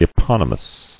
[e·pon·y·mous]